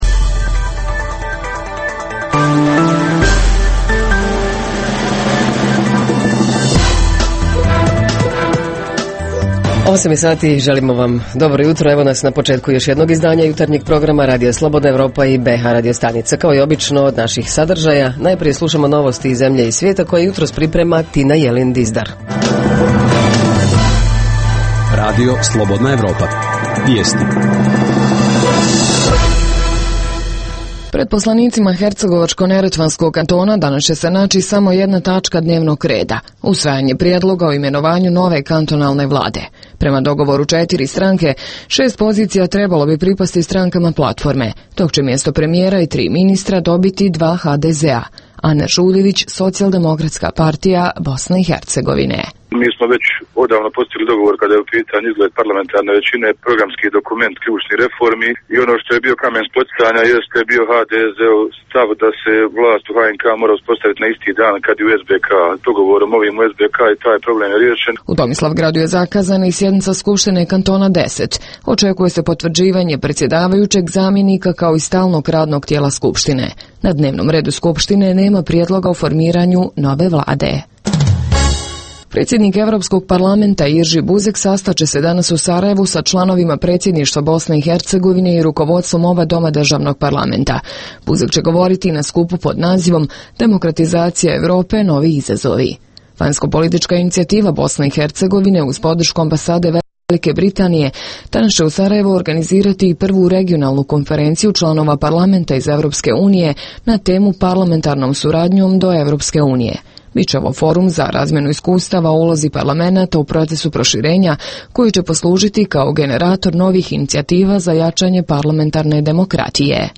- Reporteri iz cijele BiH javljaju o najaktuelnijim događajima u njihovim sredinama.
- Redovni sadržaji jutarnjeg programa za BiH su i vijesti i muzika.